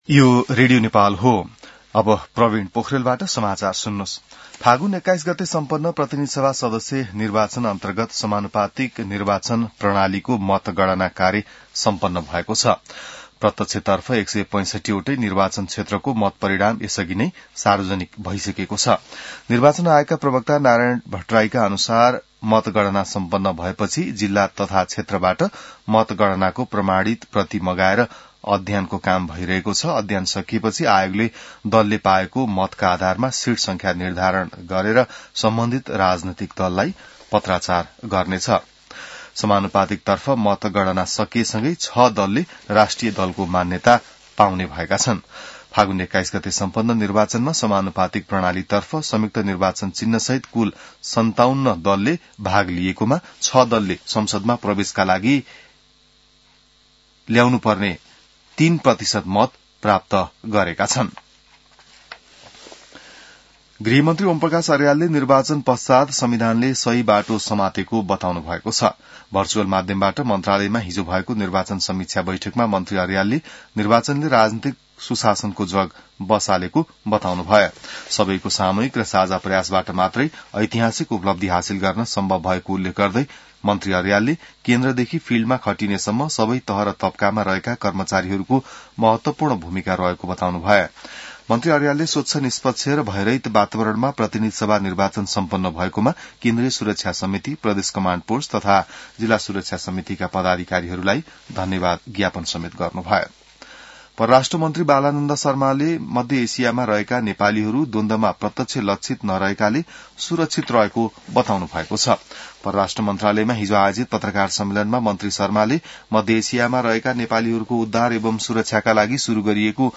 बिहान ६ बजेको नेपाली समाचार : २८ फागुन , २०८२